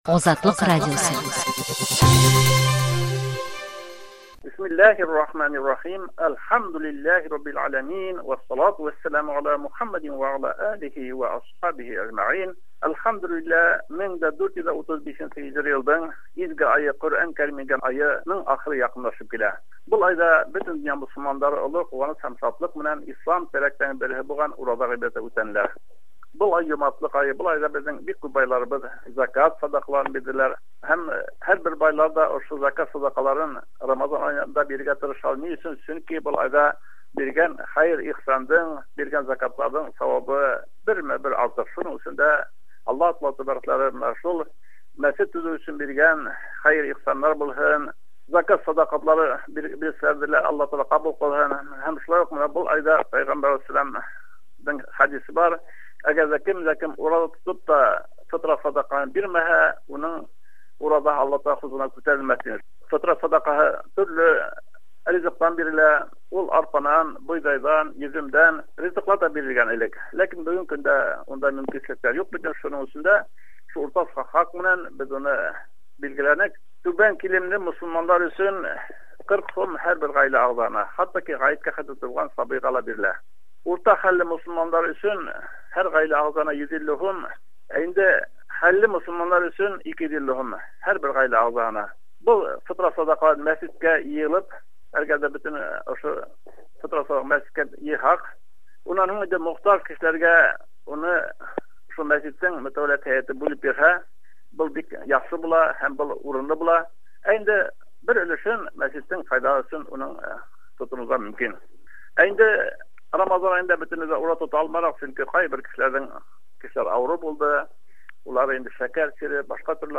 Башкортстан мөселманнары дини идарәсе җитәкчесе Нурмөхәммәт хәзрәт Хисамов Рамазан ае тәмамланганда Азатлык тыңлаучыларына фидия, фитр һәм зәкят сәдәкаләре турында мәгълүмат бирә.